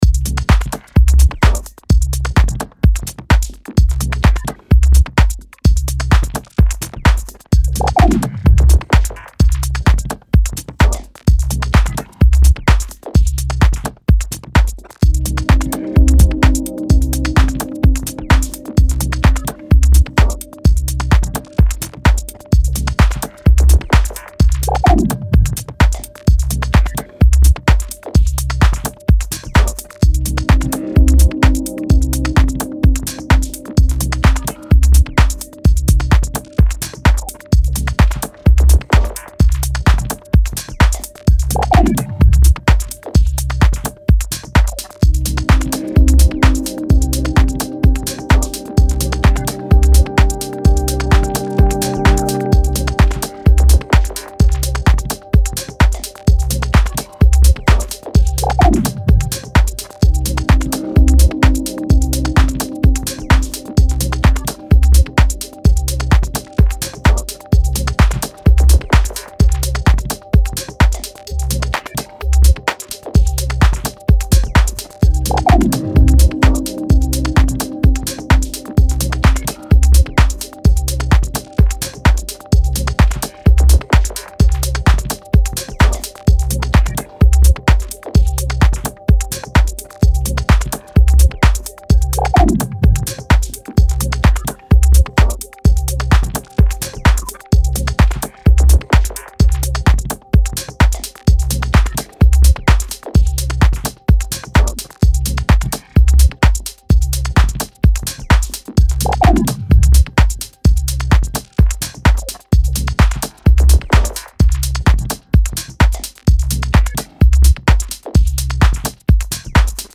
いずれもストイックに研ぎ澄まされたグルーヴ